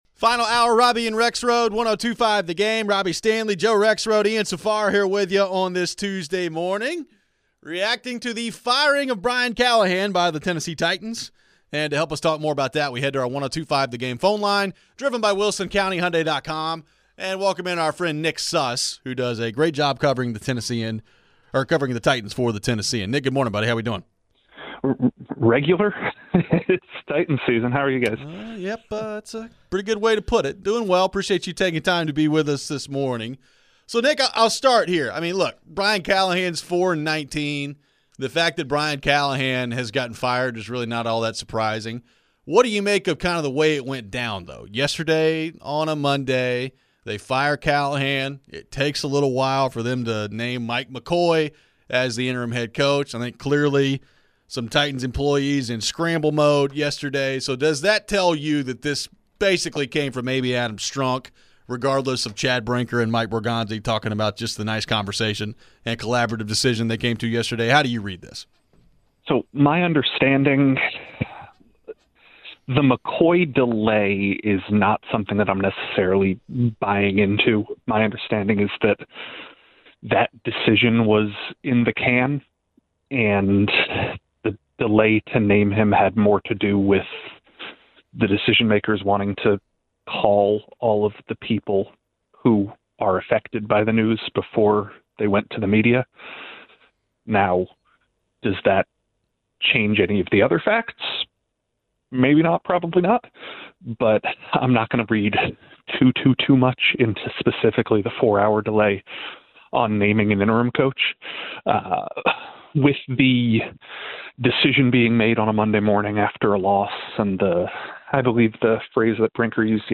We head back to the phones. Why was the Brinker/Borgonzi presser so awkward?
Where do the Titans go from here, and what should they look for in the next HC? We wrap up the show with your final phones.